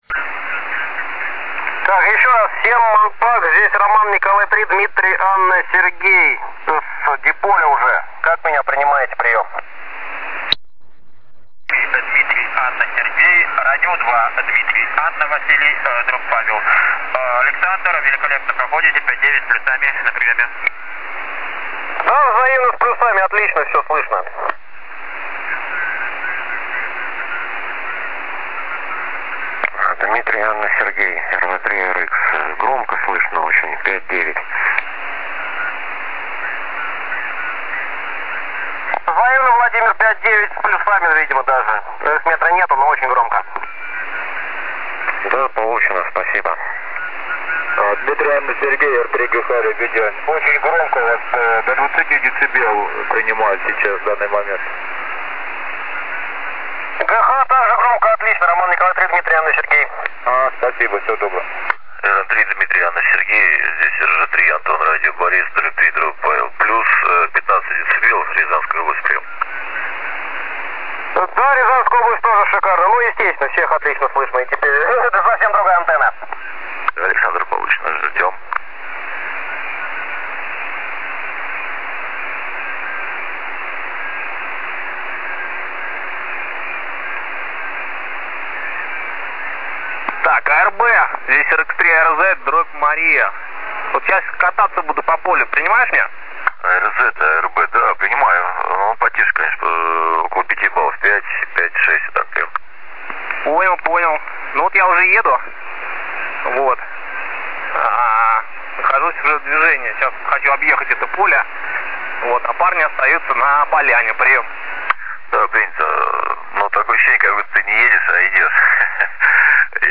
Начало » Записи » Записи радиопереговоров - любители и пираты